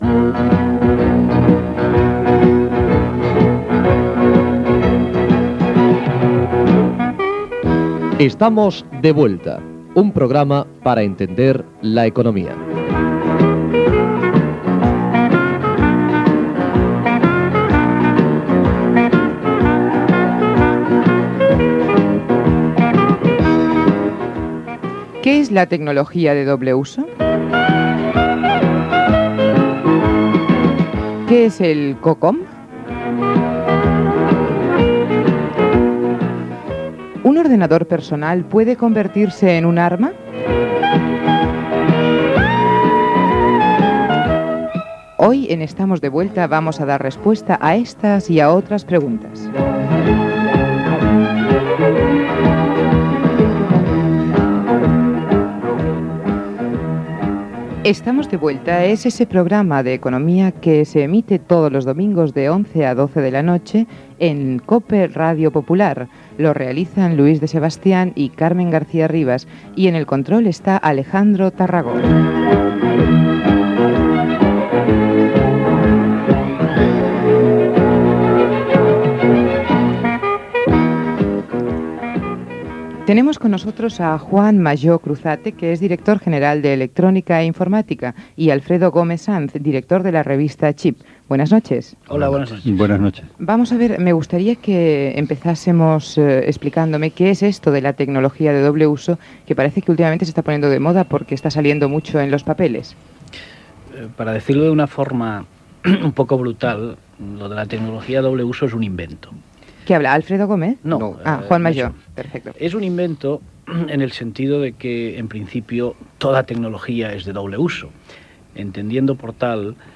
Careta del programa d'economia.
Divulgació